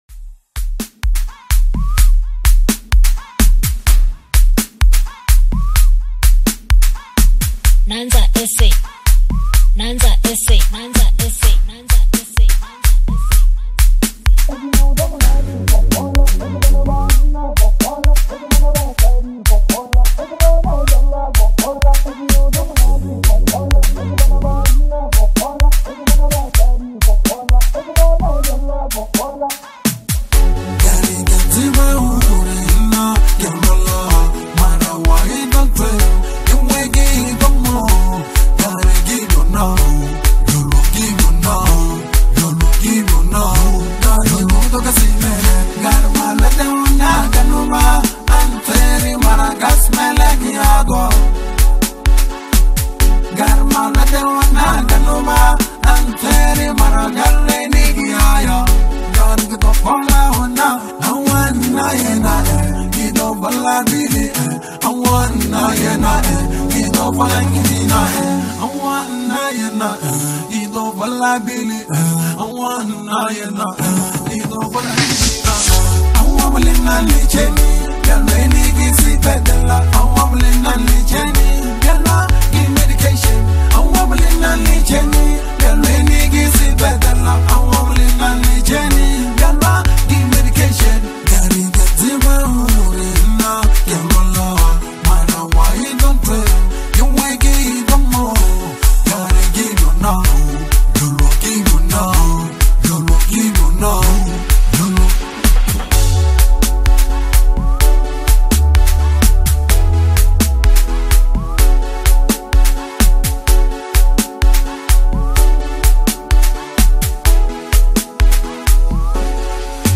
dynamic vocals